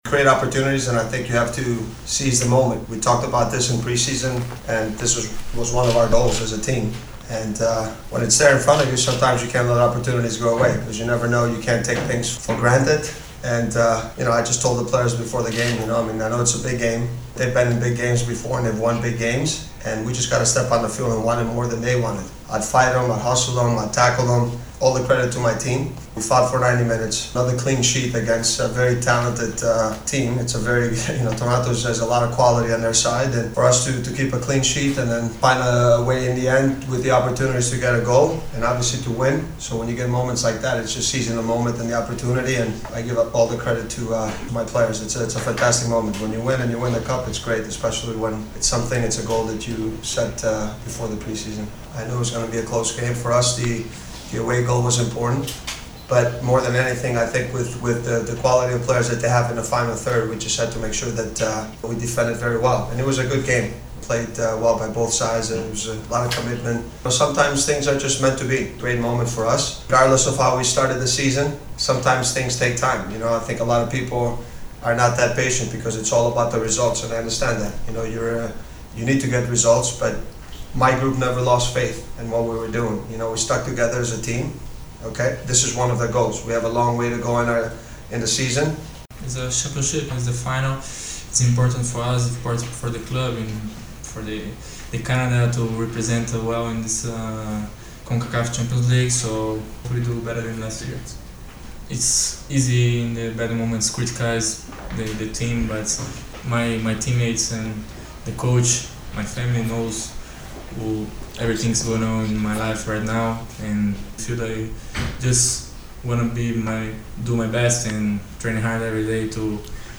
interviste.mp3